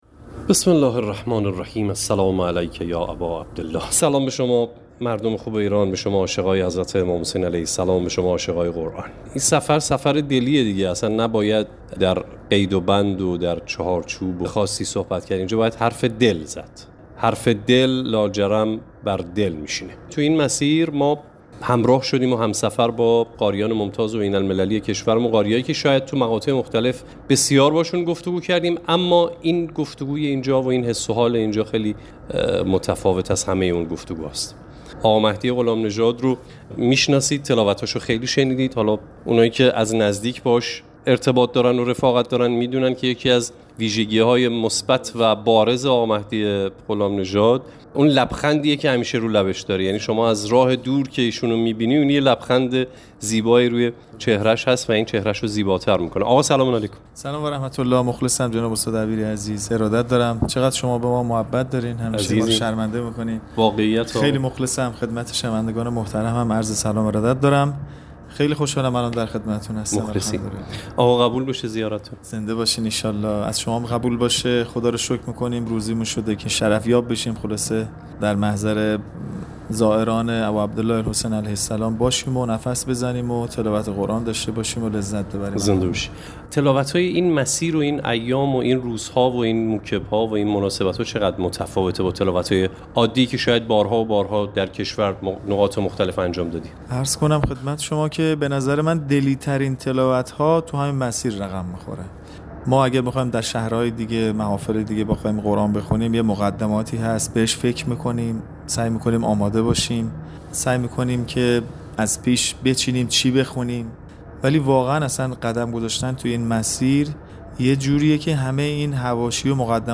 وی در گفت‌وگو با ویژه‌برنامه اربعینی رادیو قرآن درباره حضور در بین زائران و تلاوت‌هایش در مسیر مشایه گفت: خدا را شکر می‌کنم که در محضر زائران اباعبدالله الحسین(ع) هستم، نفس می‌زنم و تلاوت قرآن دارم.